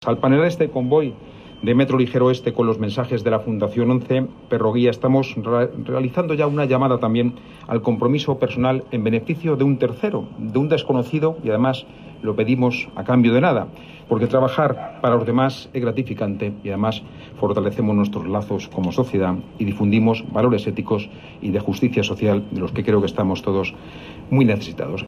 El consejero de Transportes, Movilidad e Infraestructuras,